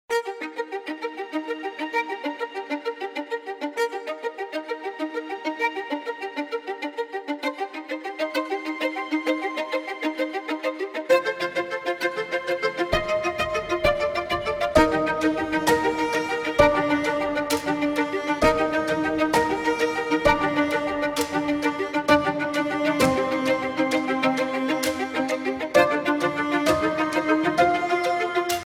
Category: Classical